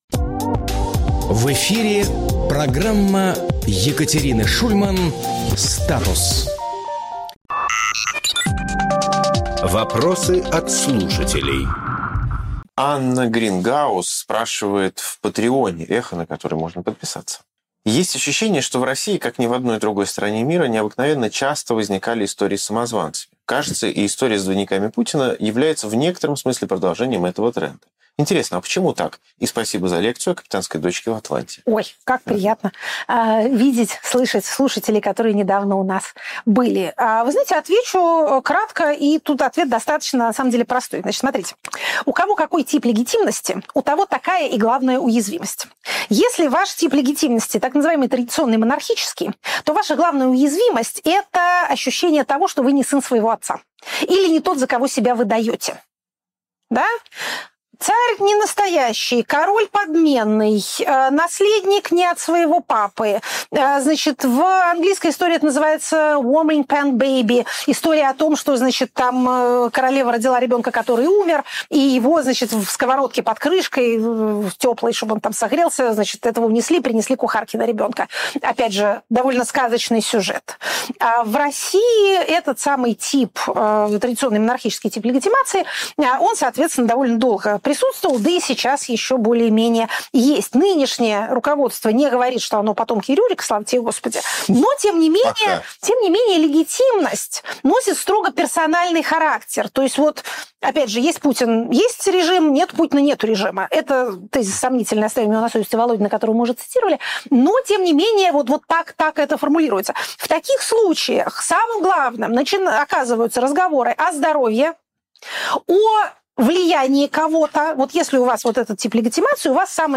Екатерина Шульманполитолог
Фрагмент эфира от 14.04.26